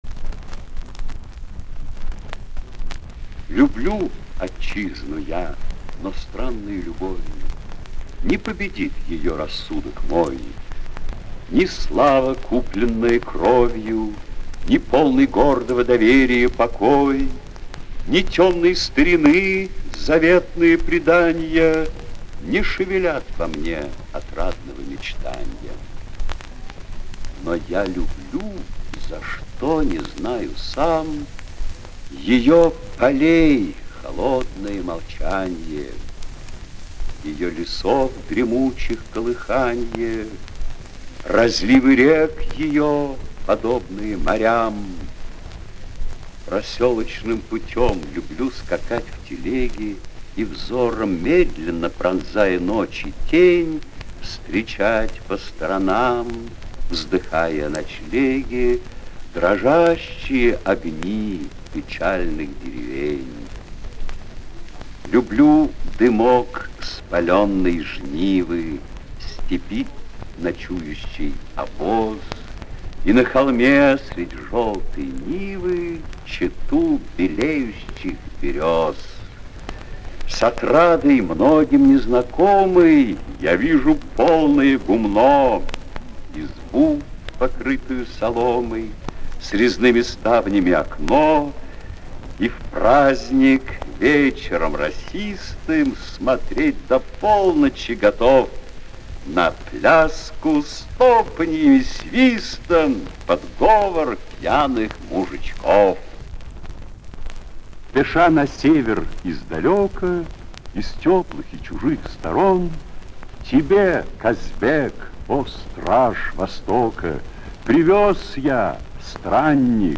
Звуковая страница 11 - В.И.Качалов читает стихи Лермонтова.